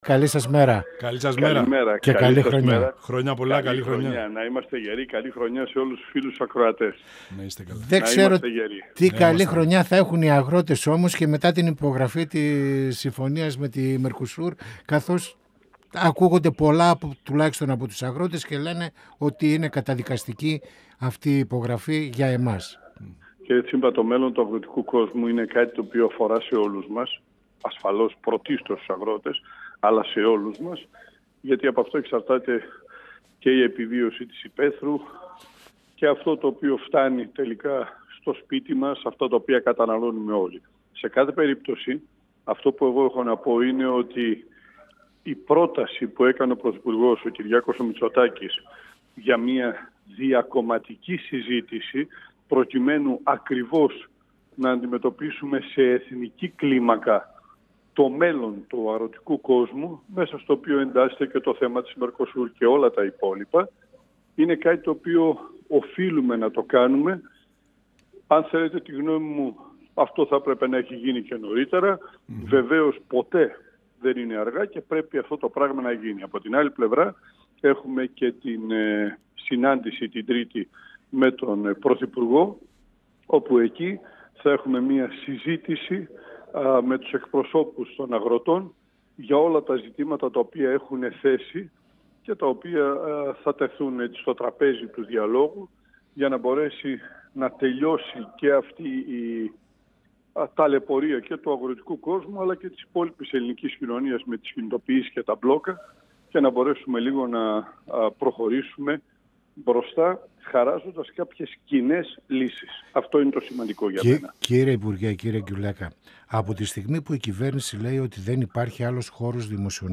Στις πολιτικές εξελίξεις, στα αγροτικά μπλόκα και στο σύνθετο πολιτικό τοπίο από τη δημιουργία νέων κομμάτων αναφέρθηκε ο Υφυπουργός Εσωτερικών- Αρμόδιος για θέματα Μακεδονίας και Θράκης Κωνσταντίνος Π. Γκιουλέκας, μιλώντας στην εκπομπή «Πανόραμα Επικαιρότητας» του 102FM της ΕΡΤ3.
Συνεντεύξεις